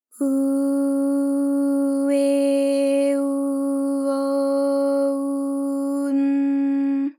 ALYS-DB-001-JPN - First Japanese UTAU vocal library of ALYS.
u_u_e_u_o_u_n.wav